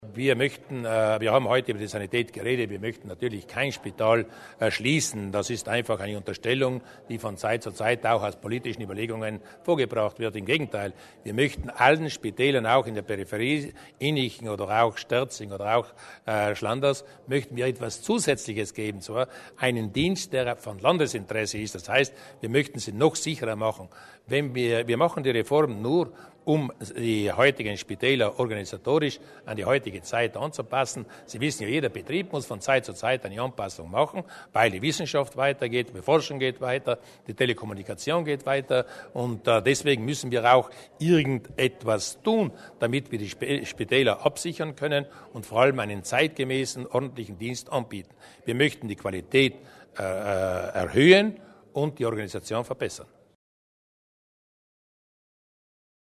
Der Landeshauptmann zur klinischen Reform